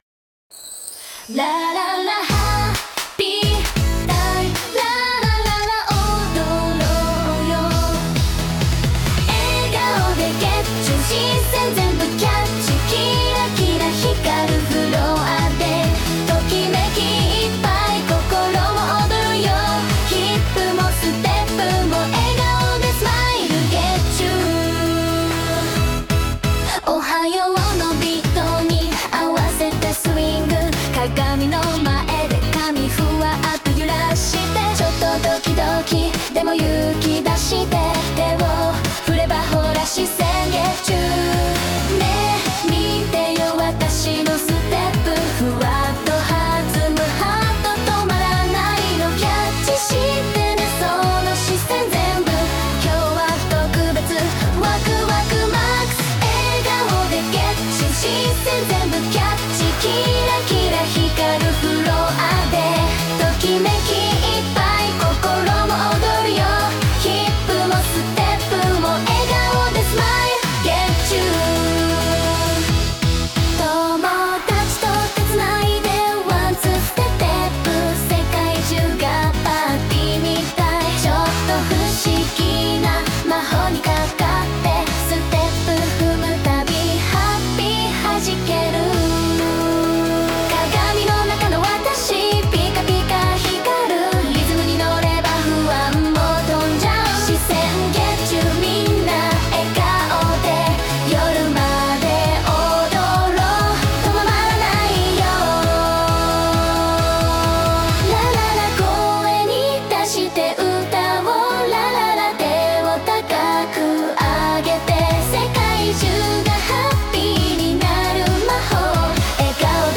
ステージのきらめきを感じるアイドルチューン